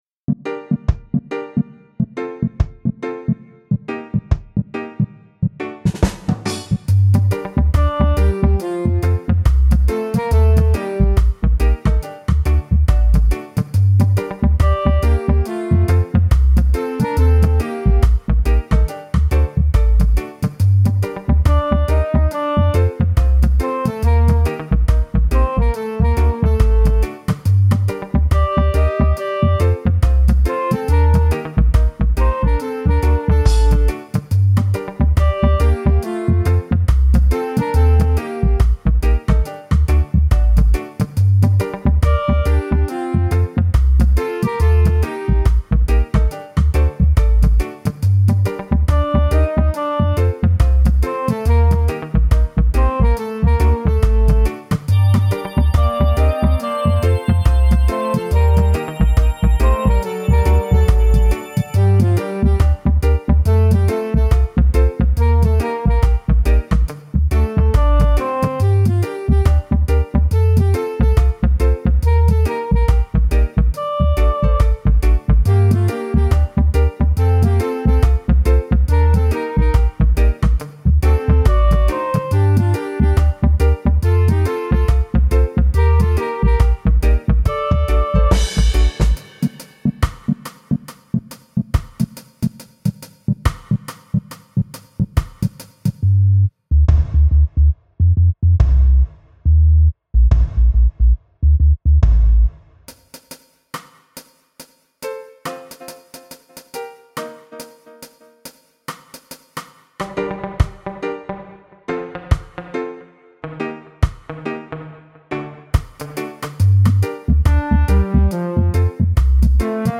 Reggae Music